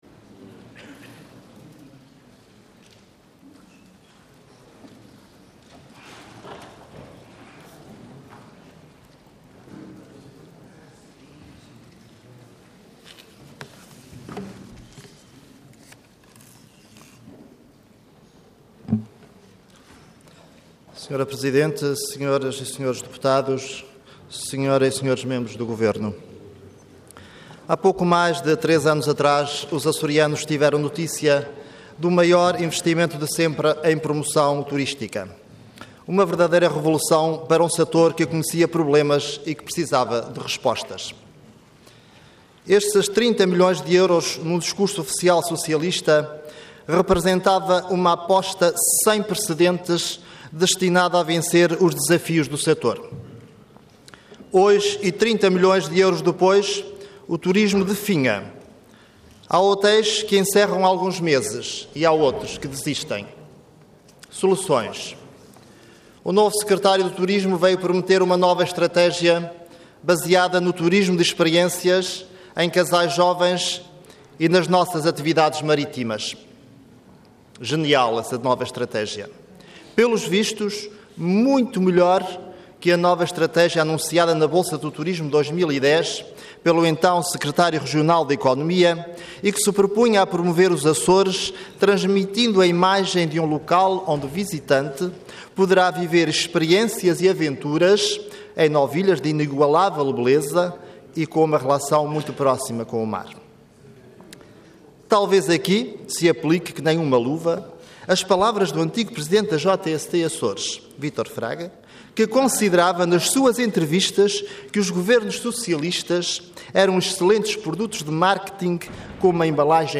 Parlamento online - Intervenção do Deputado António Pedroso do PSD - Plano e Orçamento 2013 - Turismo.
Website da Assembleia Legislativa da Região Autónoma dos Açores
Intervenção Intervenção de Tribuna Orador António Pedroso Cargo Deputado Entidade PSD